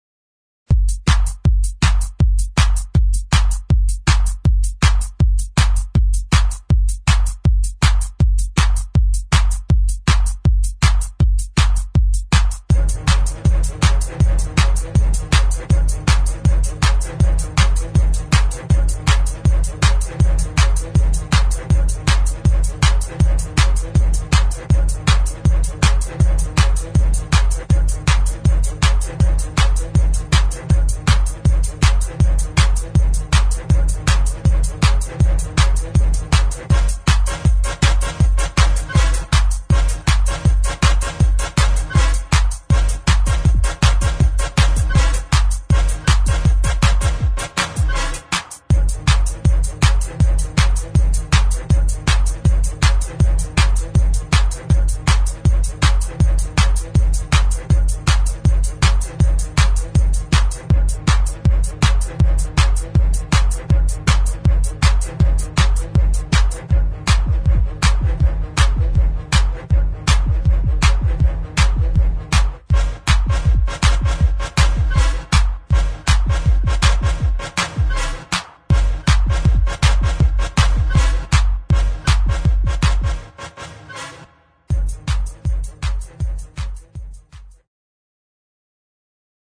[ GHETTO | TECHNO | ELECTRONIC ]